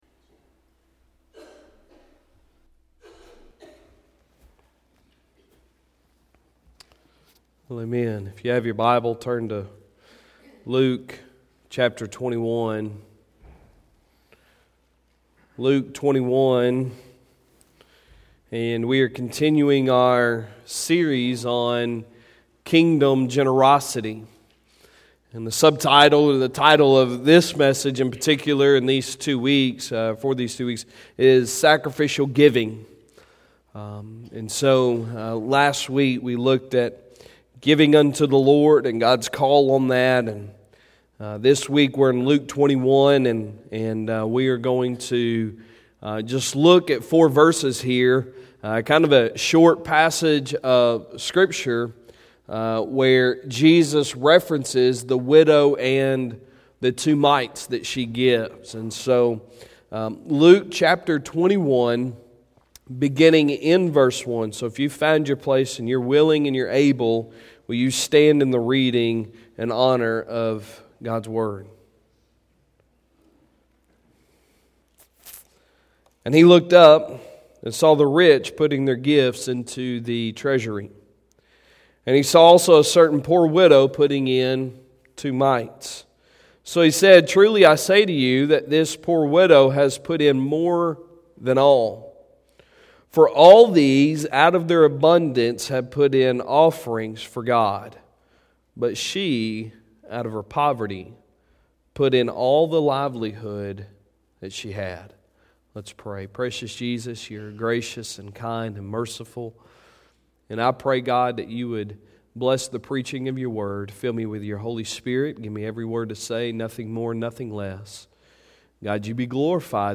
Sunday Sermon December 8, 2019